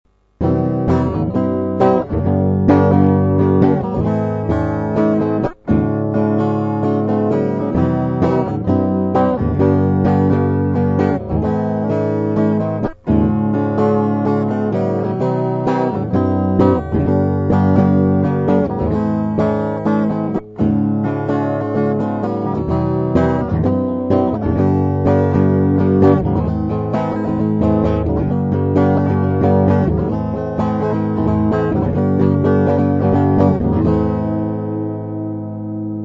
mp3 - припев